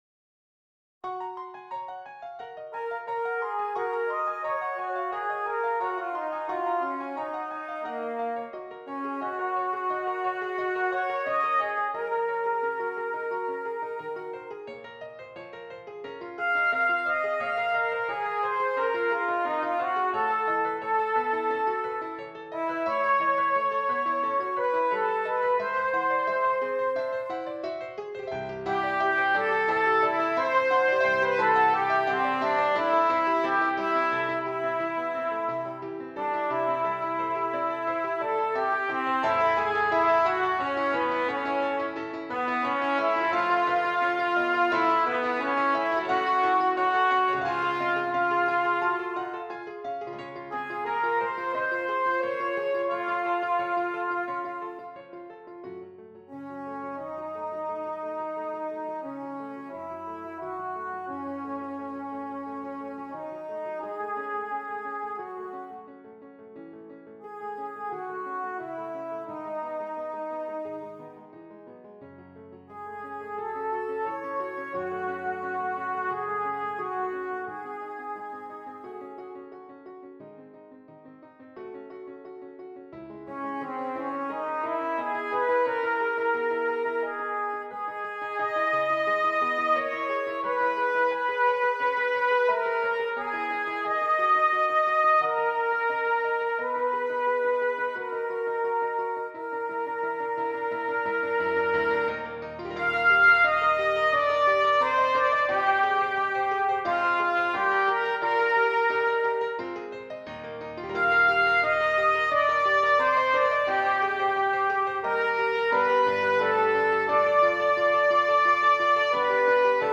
Trumpet and Keyboard